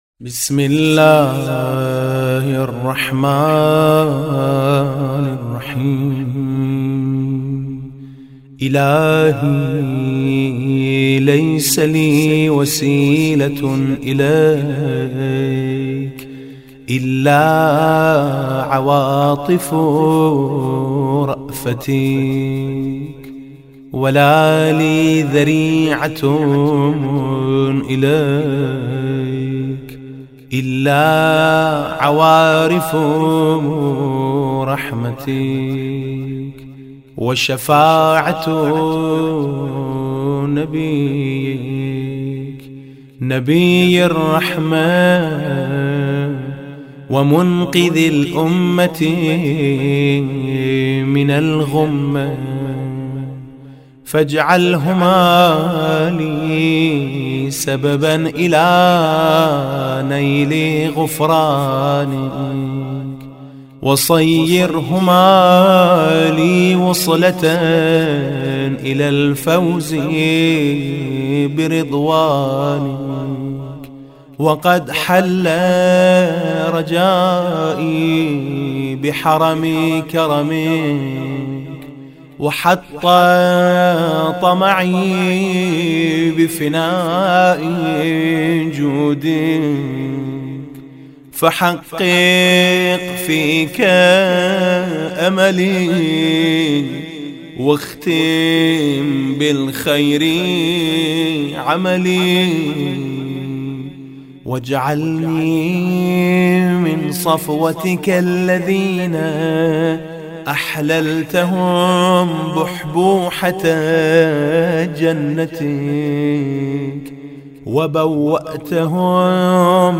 مناجات توسل کنندگان یا مناجات المتوسلین با لحن عربی از میثم مطیعی